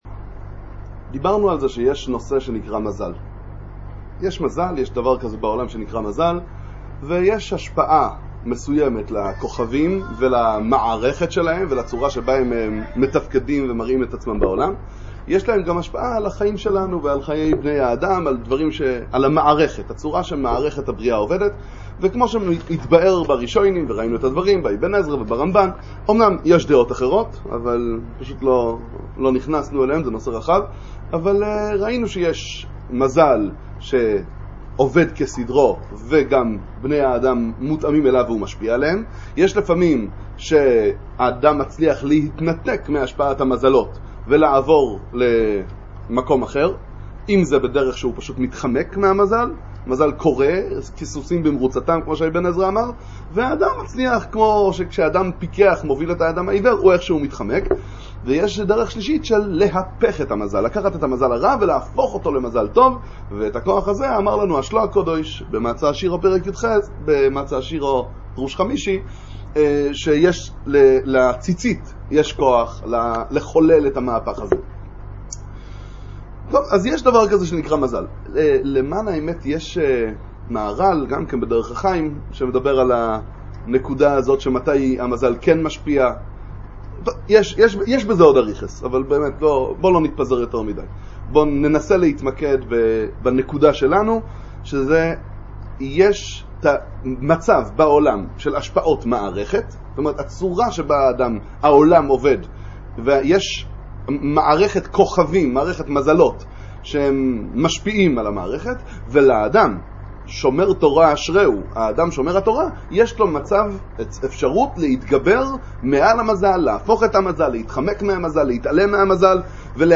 שיעור שני על כח המזלות. הסגולה הנפלאה שבכוח הציצית. לבטל את שט"ן ע"ז. להחזיר את האדם למצב שלפני החטא הקדמון.